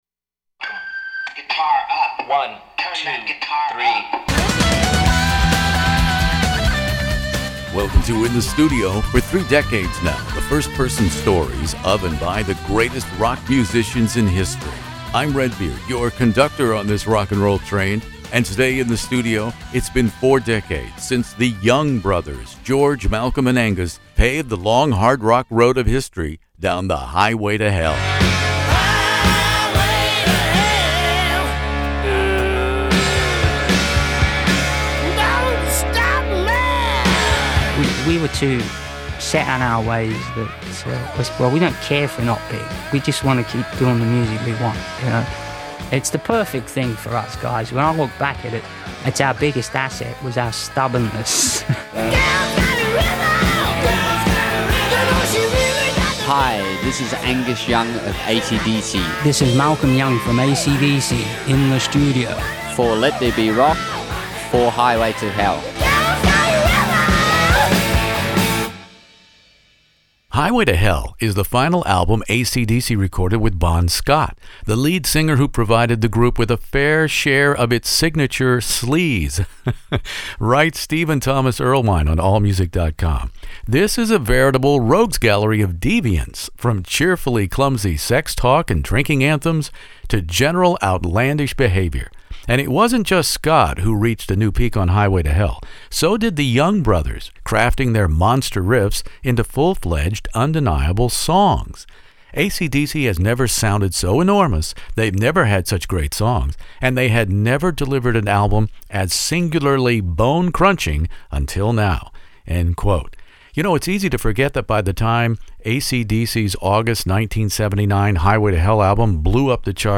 AC/DC “Highway to Hell” interview with Angus Young and the late Malcolm Young In the Studio
acdc-highway2hell-interview.mp3